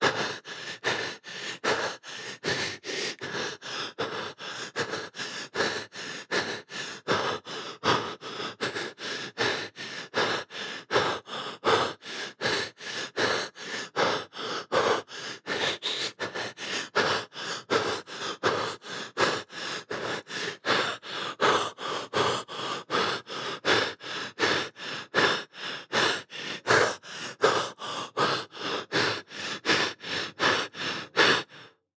SFX男呼吸快音效下载
SFX音效